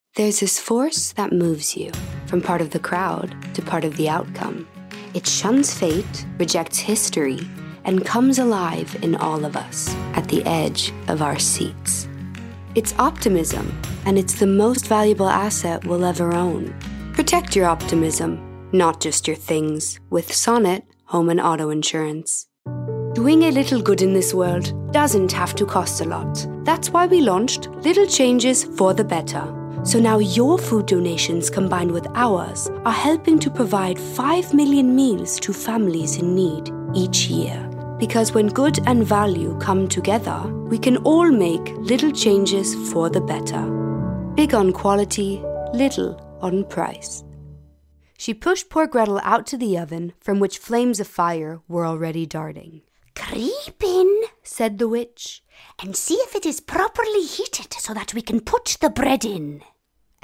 European, German, Swiss-German, Female, Home Studio, 20s-30s